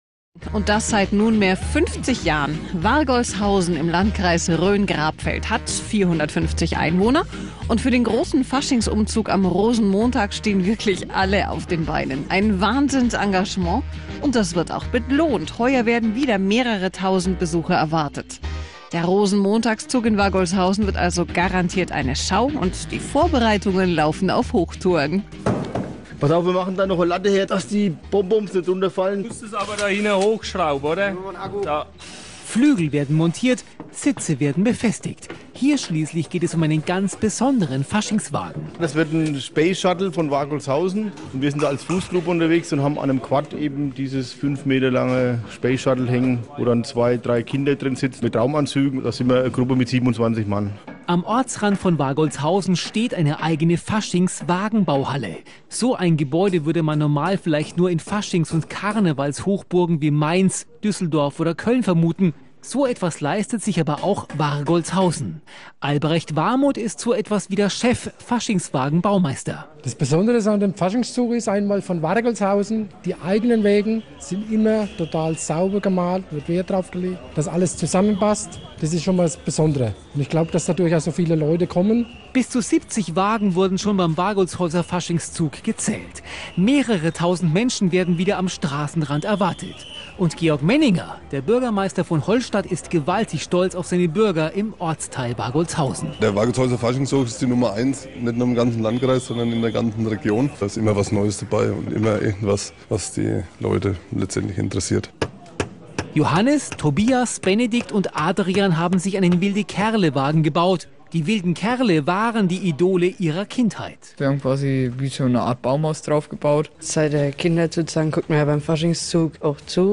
50 Jahre Rosenmontagszug – ein Ereignis, das seine Schatten vorauswirft. Im Vorfeld des Jubiläumsumzugs war die Welle Mainfranken in Wargolshausen und hat Stimmen eingefangen.
Gesendet wurde der Beitrag von der „Welle Mainfranken“ am Freitag, 24.2.2017 zwischen 12.00 Uhr und 13.00 Uhr (Bayern1).